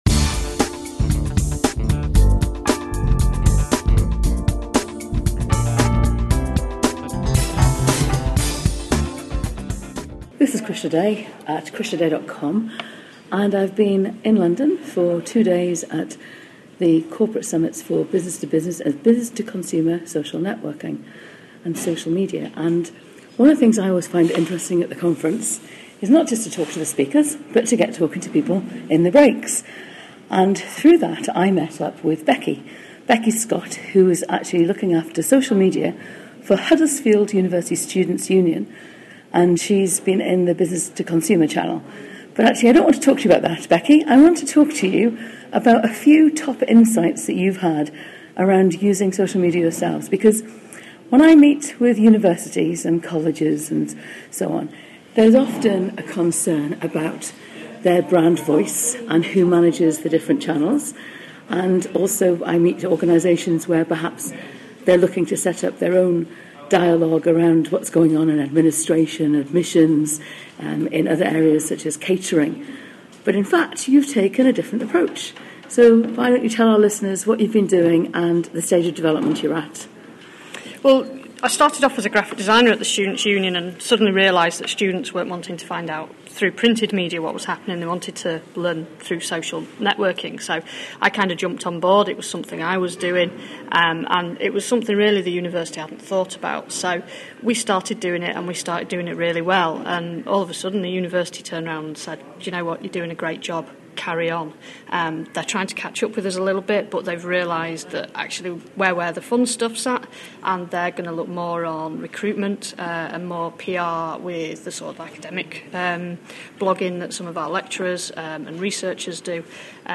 In this interview we talk to a delegate of the UsefulSocial Corporate B2B Social Media Summit